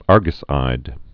(ärgəs-īd)